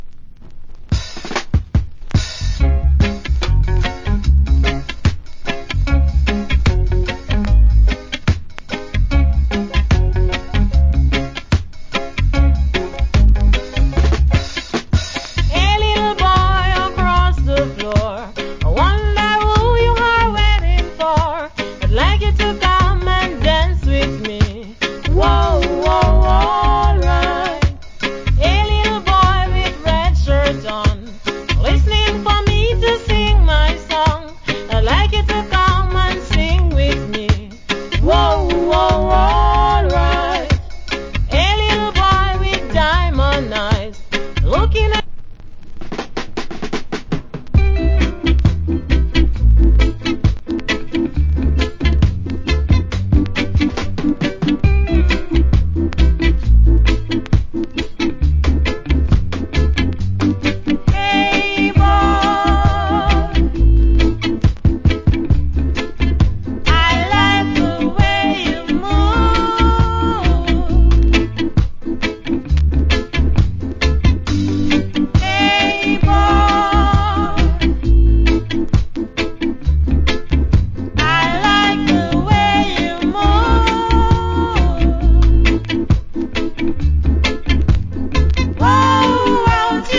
Nice Female Reggae Vocal.